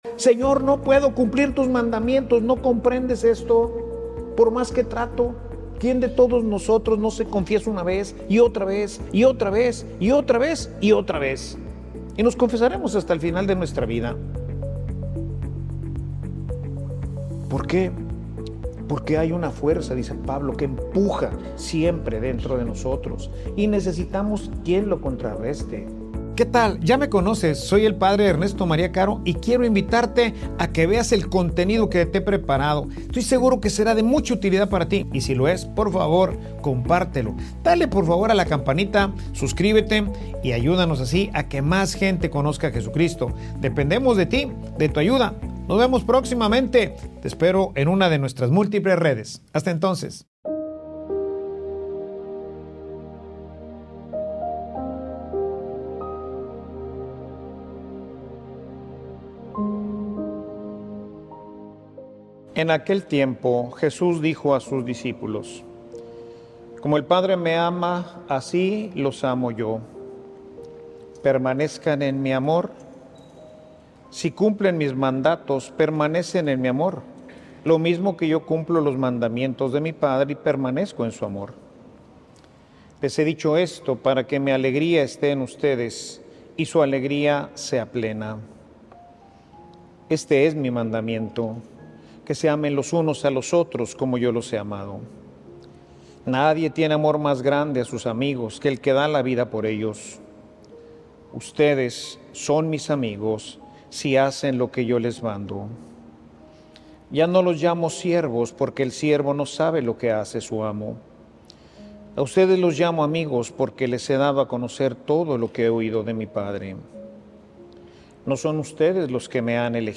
Homilia_Sin_Dios_es_imposible_amar.mp3